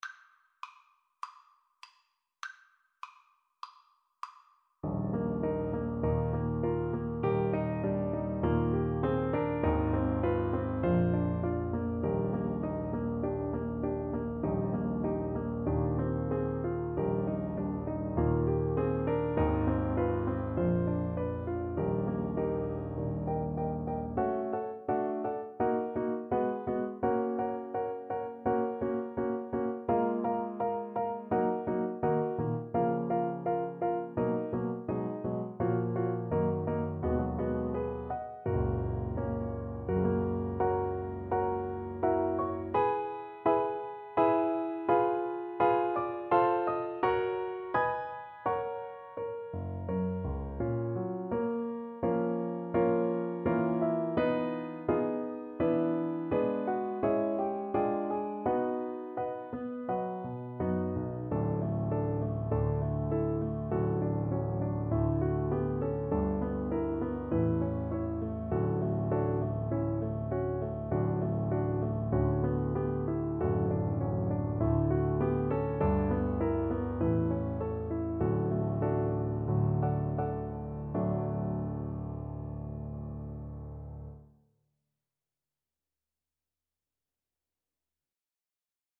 Moderato
Classical (View more Classical Clarinet Music)